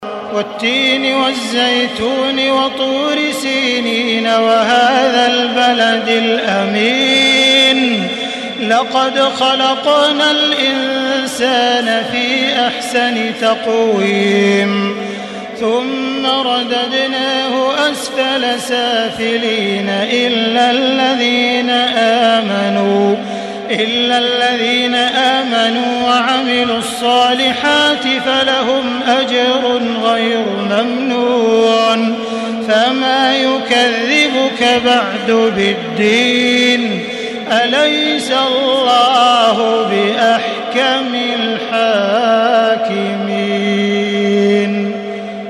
Surah التين MP3 in the Voice of تراويح الحرم المكي 1435 in حفص Narration
Listen and download the full recitation in MP3 format via direct and fast links in multiple qualities to your mobile phone.
مرتل